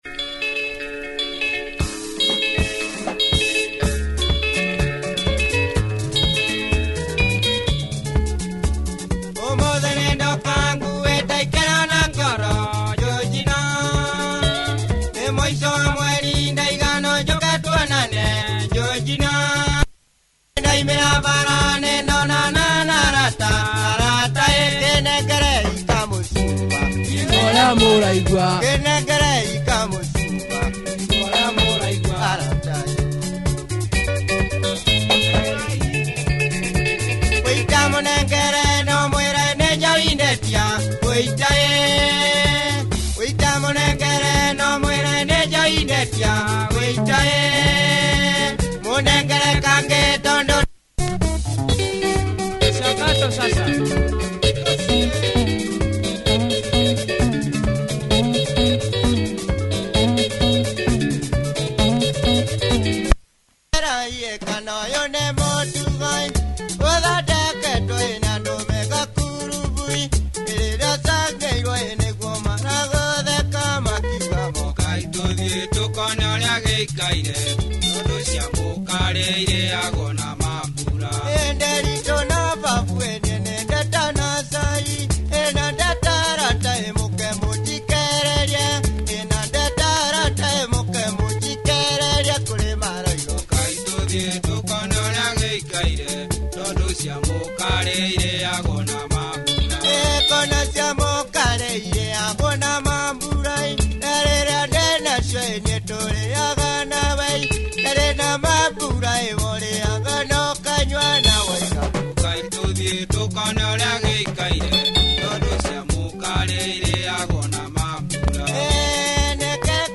Good benga dancer
lead guitar